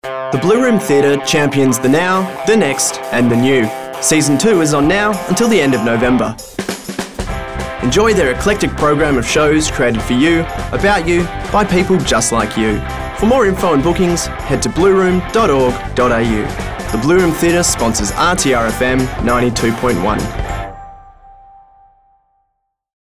Voice Reel Demo